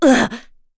Laudia-Vox_Damage_02.wav